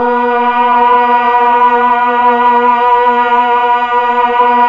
Index of /90_sSampleCDs/Keyboards of The 60's and 70's - CD1/VOX_Melotron Vox/VOX_Tron Choir